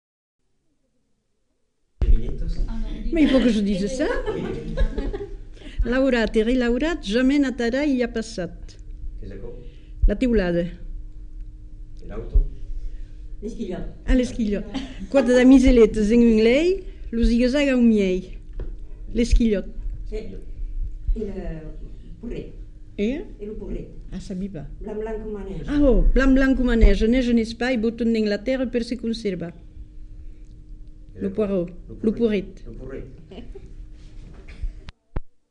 Devinettes
Aire culturelle : Marmandais gascon
Effectif : 1
Type de voix : voix de femme
Production du son : récité
Classification : devinette-énigme